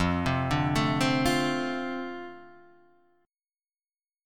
F 6th